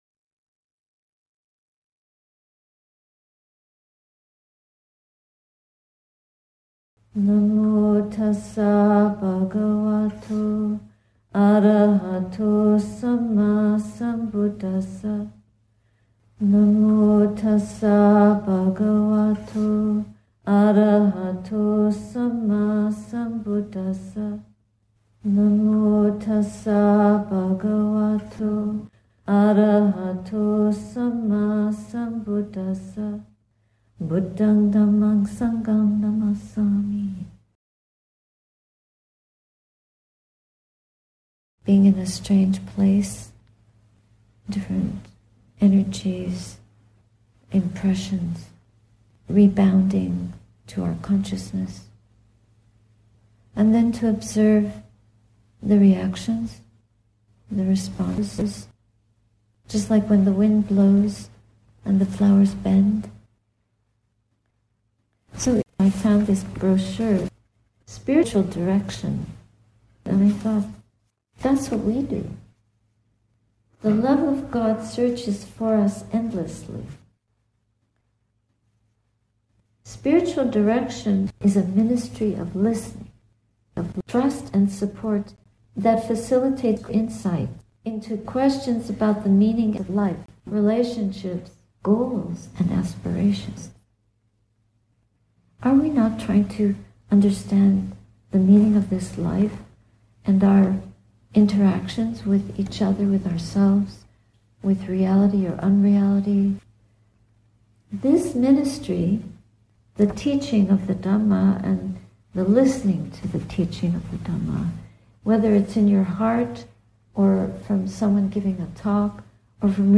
Madison Insight Meditation, Wisconsin, Oct 2024 https